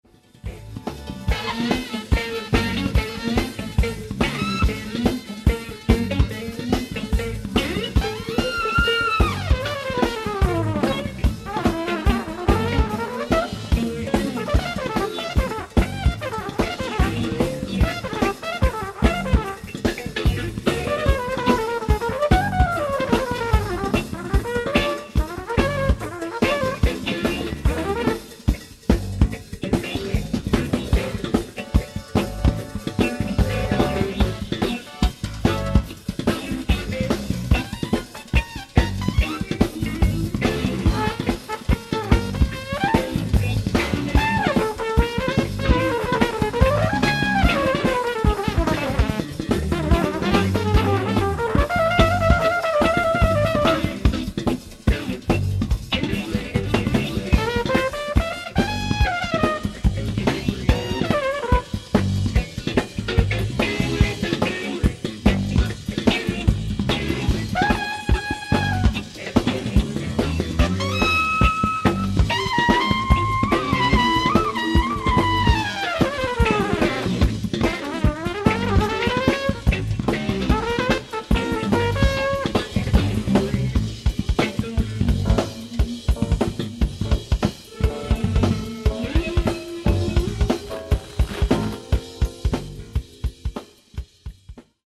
ライブ・アット・セントデニス・シアター、モントリオール、カナダ 07/07/1983
初登場となるステレオ音源でのライブ完全版！！
※試聴用に実際より音質を落としています。